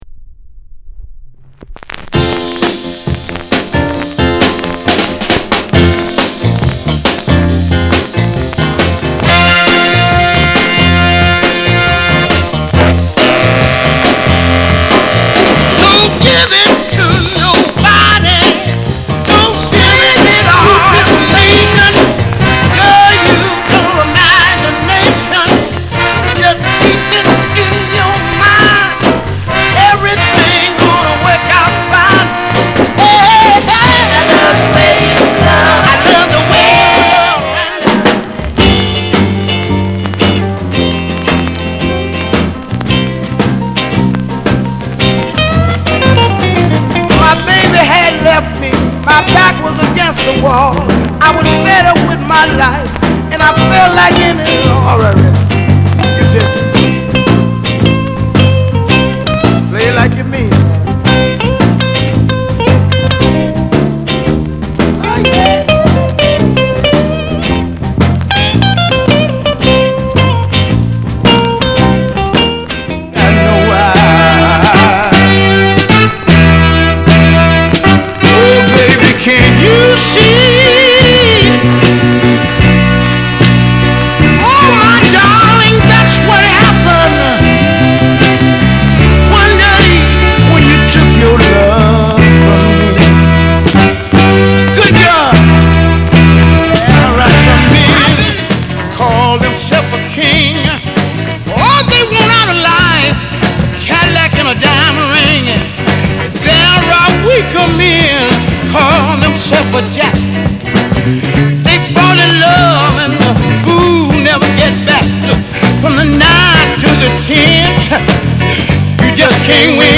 R&B、ソウル
/盤質/両面全体に傷あり/US PRESS